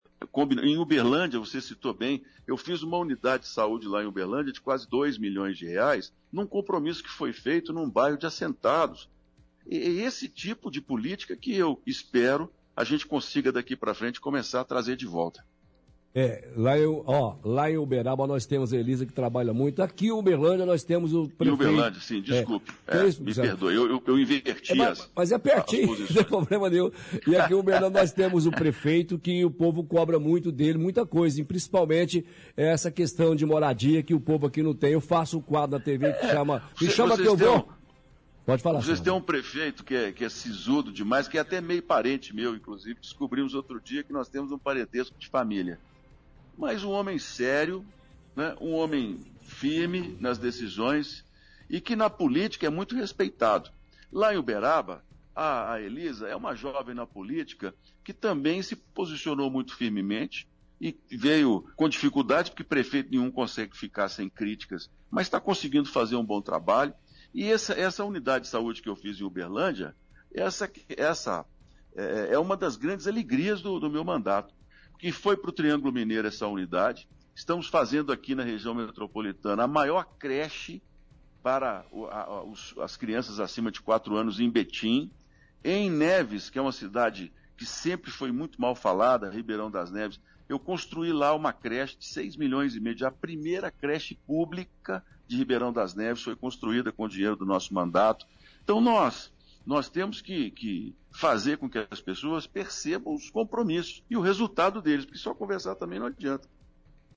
Entrevista com Carlos Viana